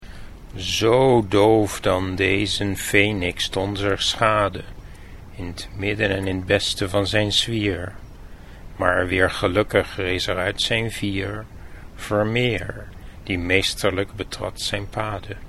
Click here to listen to the Dutch pronunciation of Arnold Bon's stanza